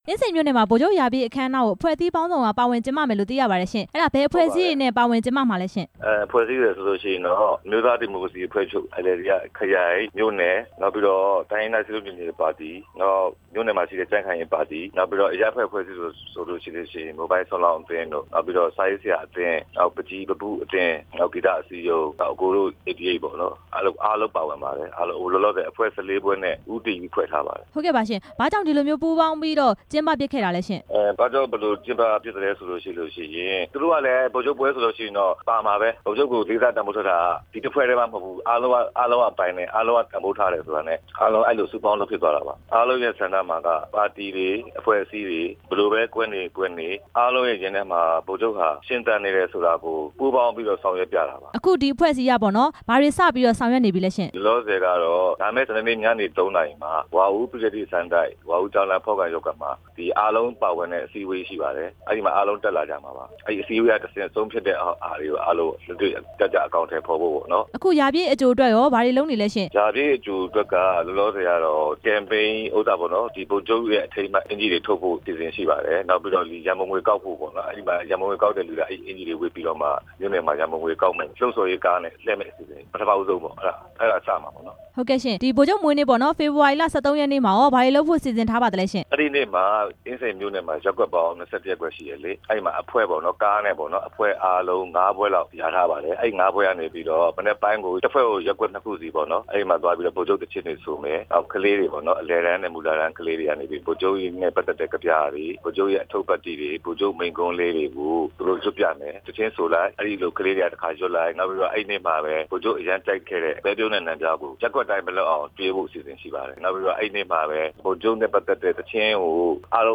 နှစ် ၁ဝဝပြည့် ဗိုလ်ချုပ်မွေးနေ့အတွက် အင်းစိန်မြို့နယ်မှာ ပြုလုပ်မယ့်အကြောင်း မေးမြန်းချက်